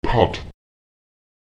Lautsprecher pat [pat] greifen, fassen, halten